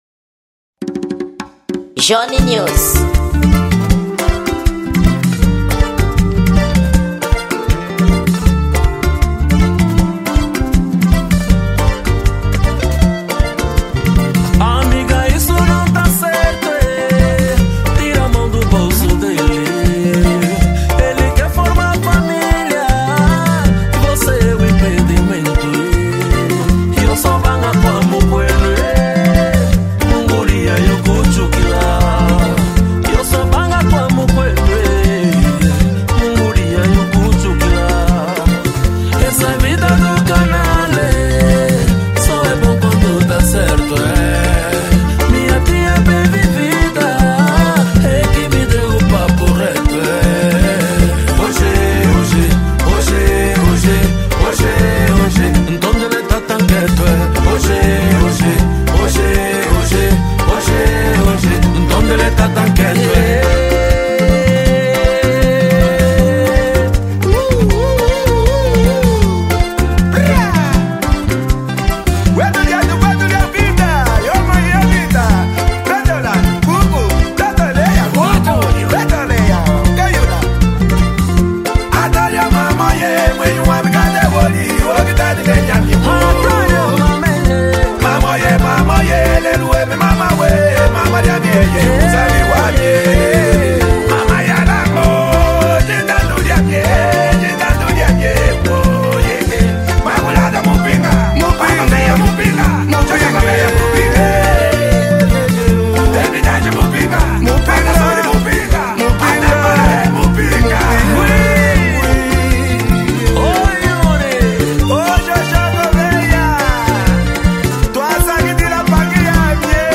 Gênero: Semba